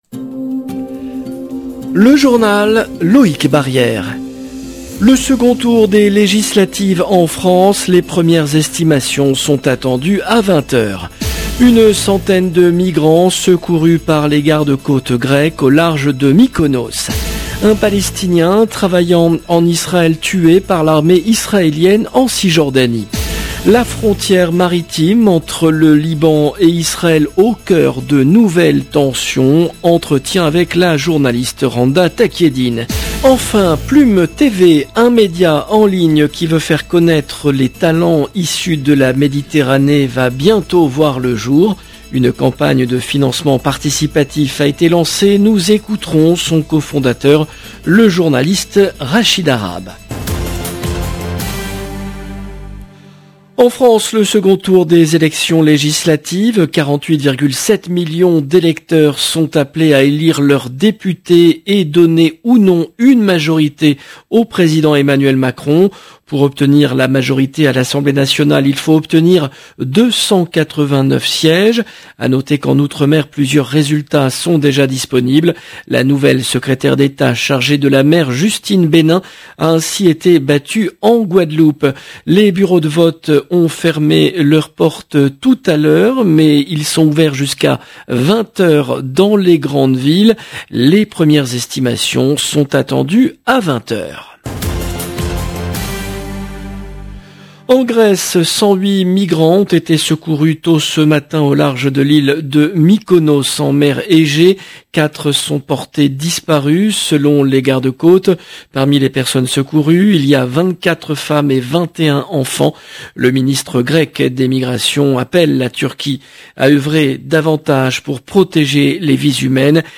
La frontière maritime entre le Liban et Israël au cœur de nouvelles tensions. Entretien